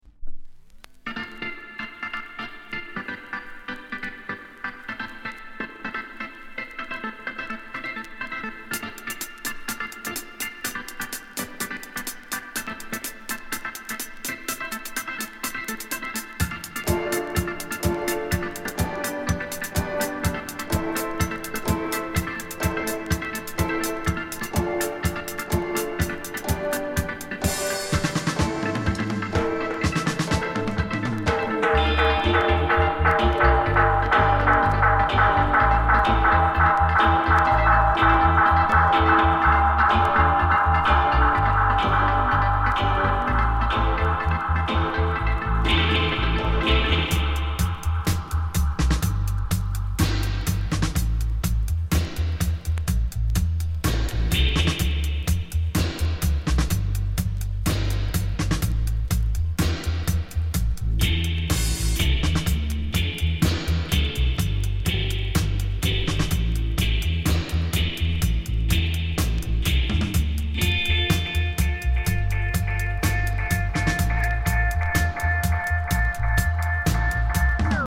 日本? 7inch/45s